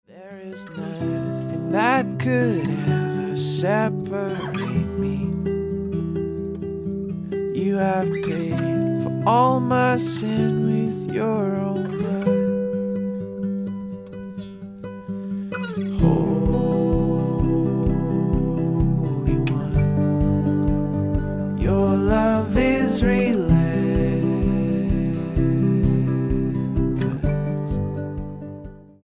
STYLE: Ambient/Meditational
is in a suitably sombre mood
with gentle acoustic guitar